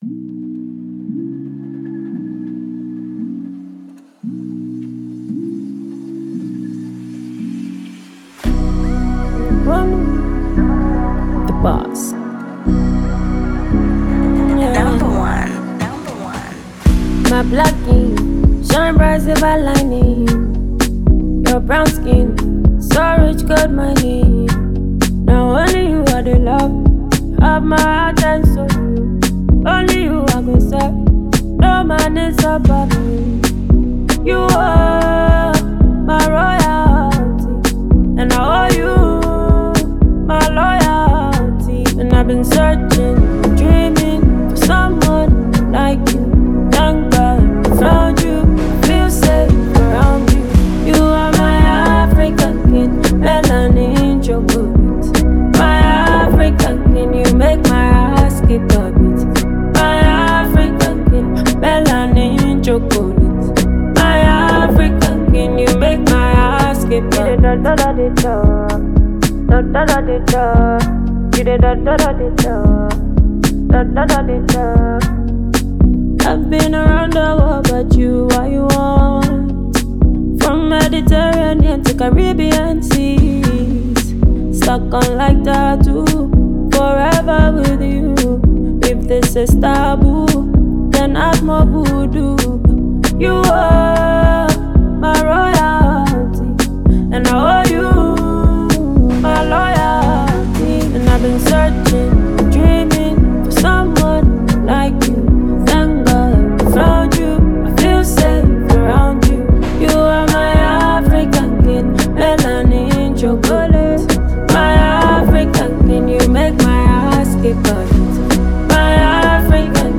Ghana Music
is a mid-tempo, guitar-led record
delivers a soulful serenade